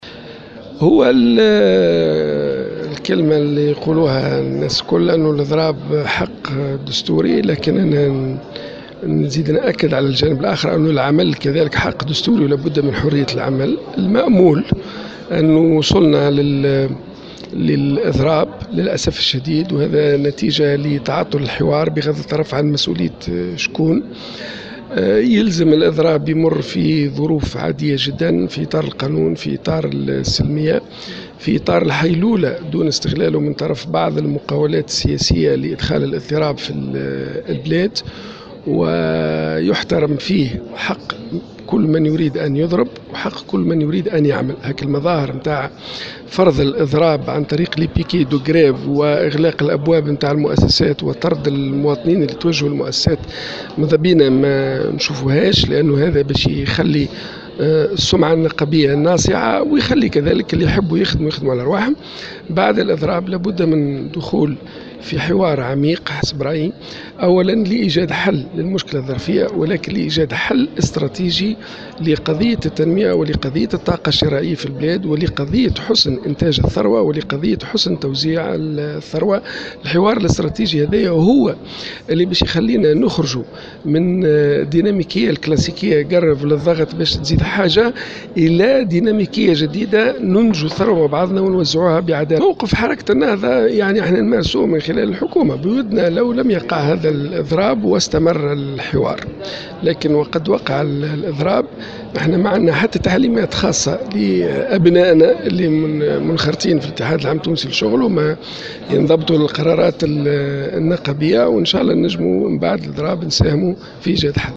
قال عبد اللطيف المكي رئيس لجنة الأمن و الدفاع بمجلس نواب الشعب في تصريح لمراسلة الجوهرة "اف ام" إن الإضراب حق دستوري لكن العمل حق دستوري أيضا و القانون يكفل حرية العمل حسب قوله.